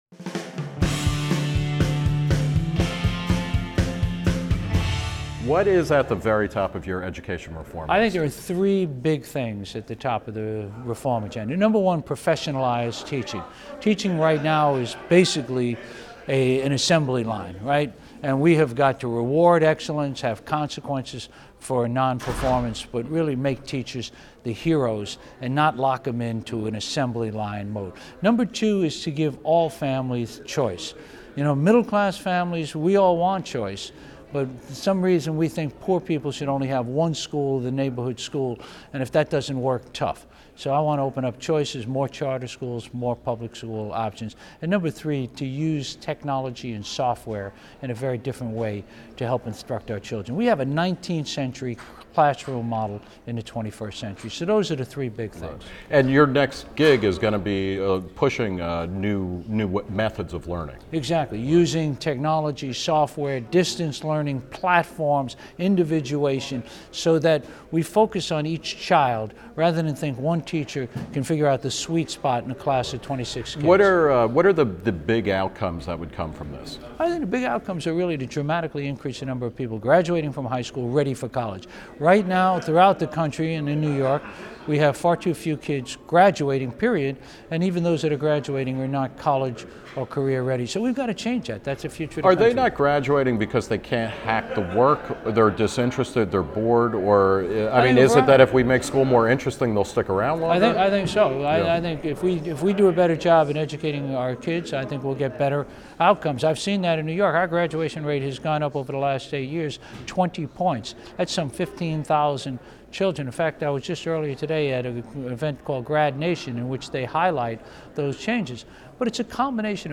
This interview is part of National School Choice Week, an initiative to raise awareness of how competition and choice can transform public education.